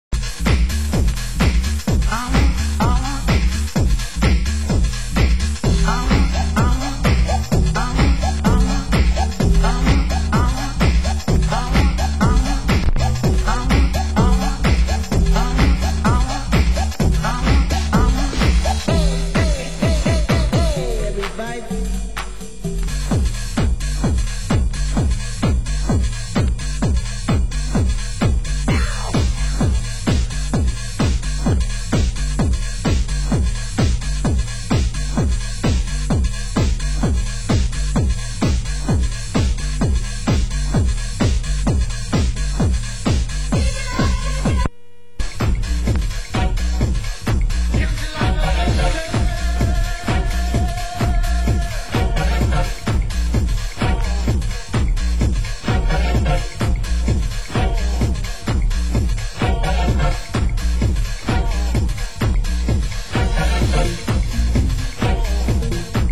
Genre: UK House